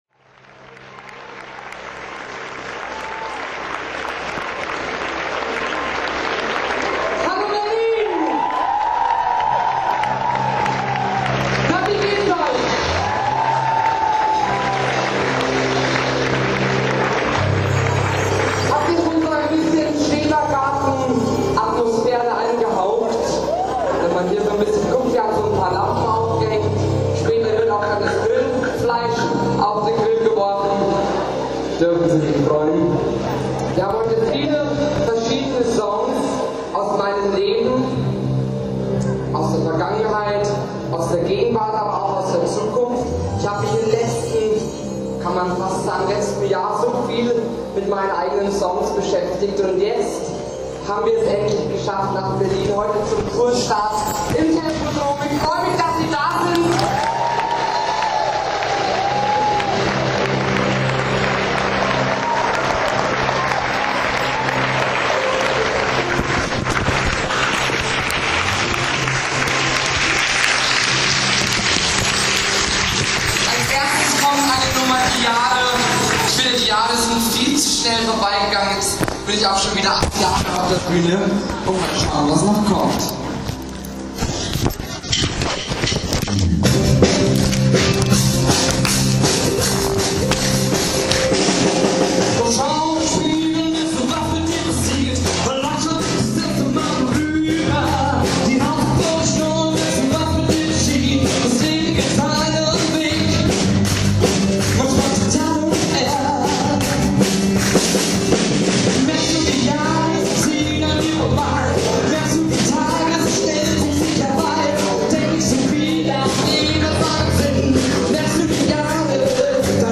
Entsprechend begeistert ging das Publikum mit.